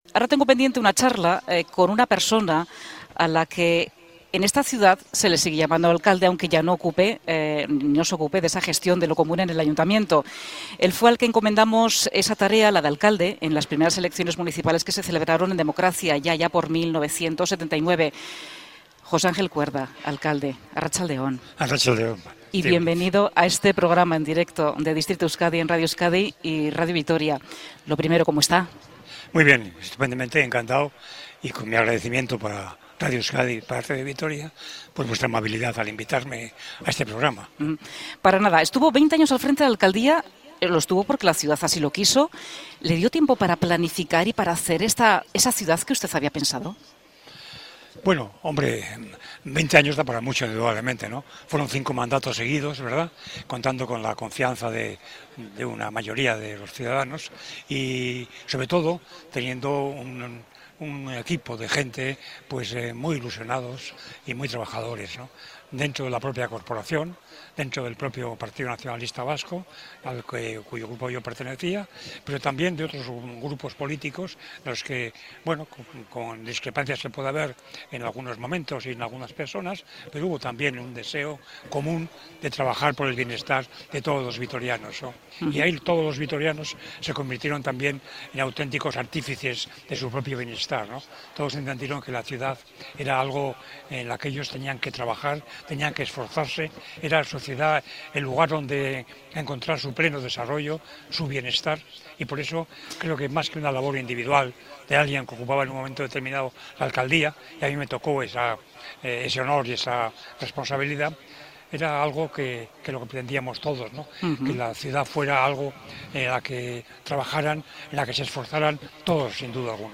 Distrito Euskadi se desplaza a Vitoria-Gasteiz, y recibe en su set de la Plaza Nueva a quien fuera alcalde de la ciudad durante 20 años: José Ángel Cuerda.